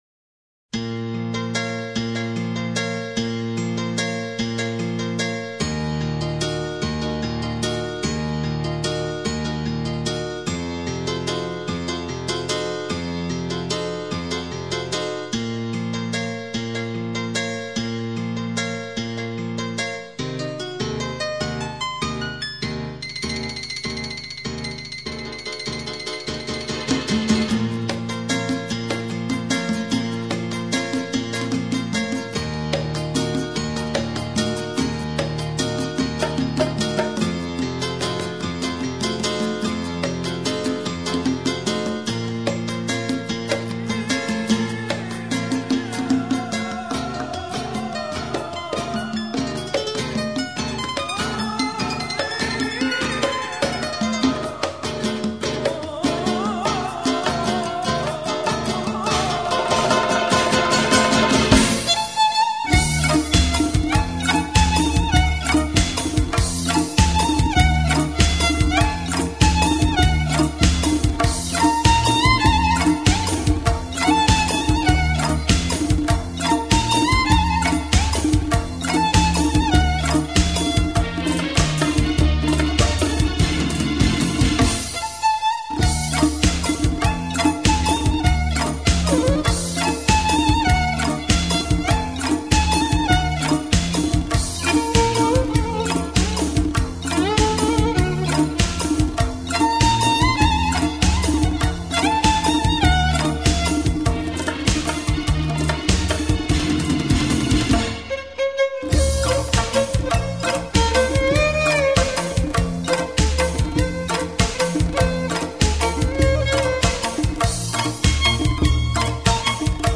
آهنگ ایرانی رقص عروس داماد